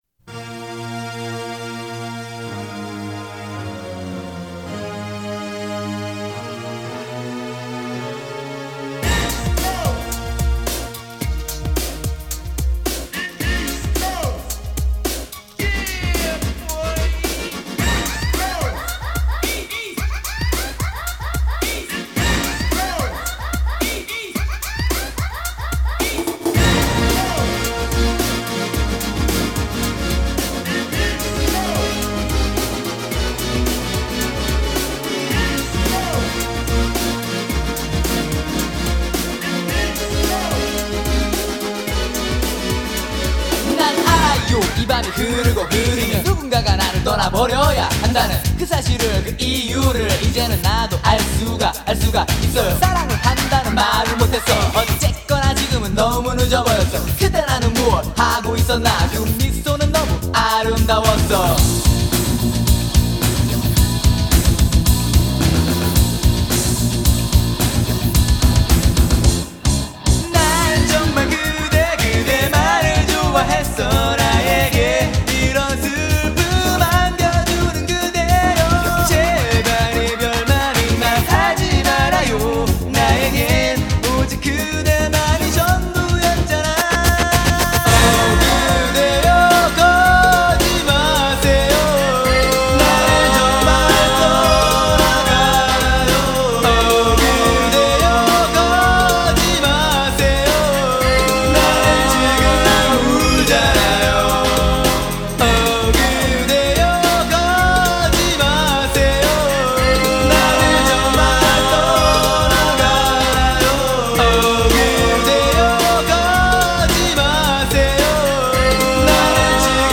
BPM109
Audio QualityPerfect (High Quality)
One of world's popular K-POPs before year 2000.